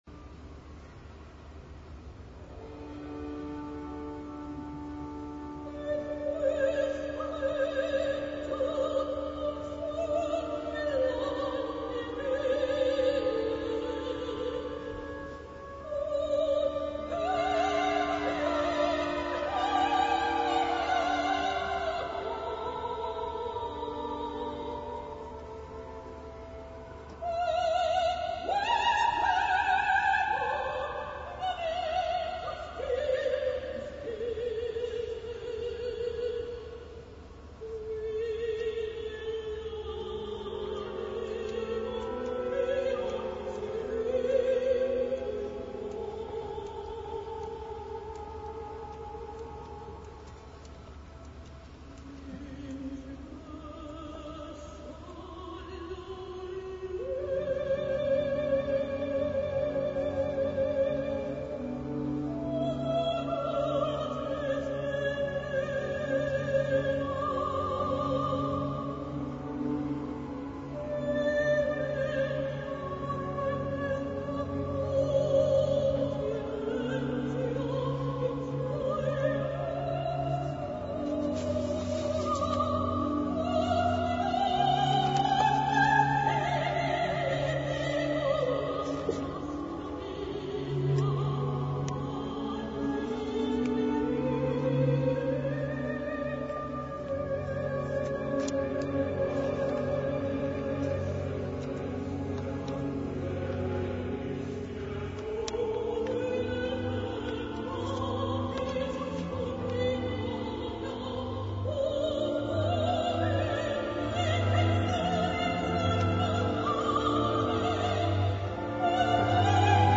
Soprano
Stagione estiva Teatro dell'Opera Roma 1999